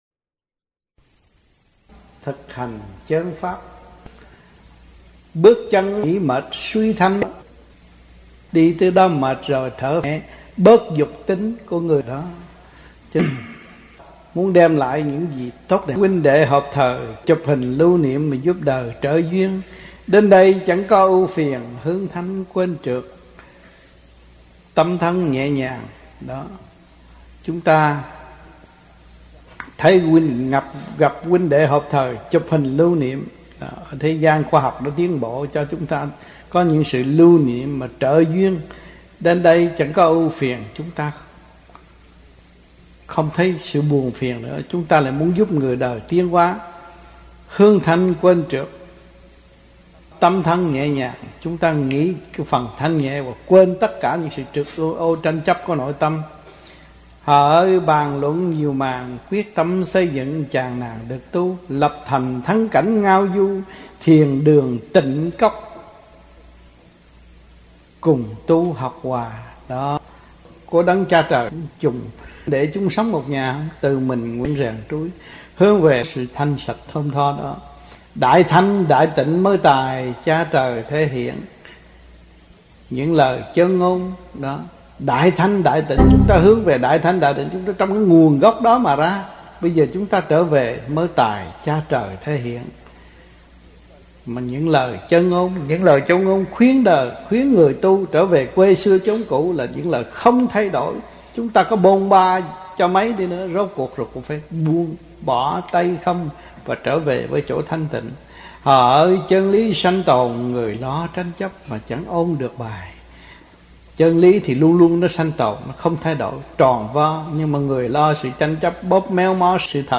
1986-06-06 - TV HAI KHÔNG - KHÓA BI TRÍ DŨNG 4 - BÀI GIẢNG BUỔI SÁNG